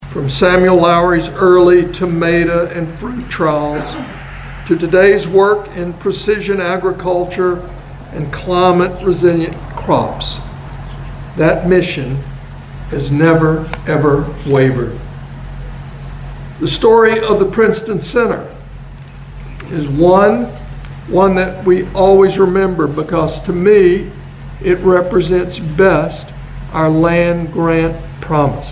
The University of Kentucky leadership and board of trustees were joined by farmers, state and local officials, and community members to celebrate a century of service at the Research and Education Center in Princeton.
University of Kentucky President Dr. Eli Capilouto said Princeton has always held a strong mission of service.